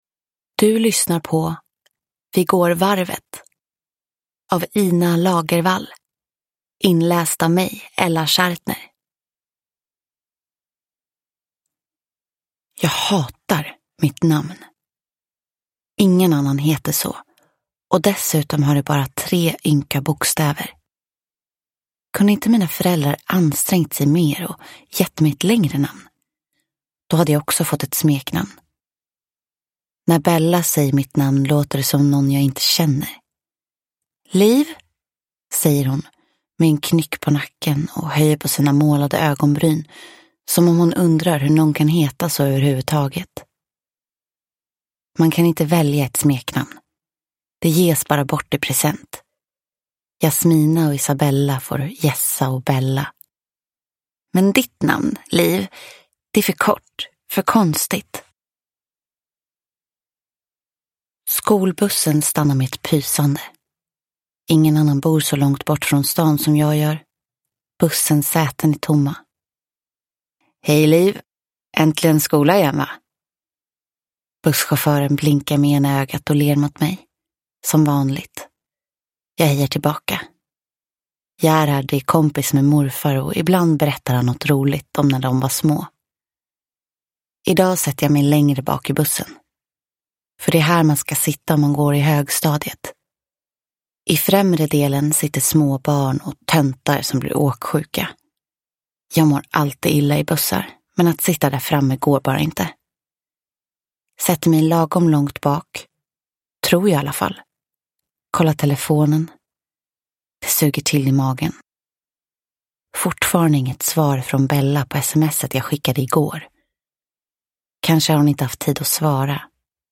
Vi går varvet – Ljudbok – Laddas ner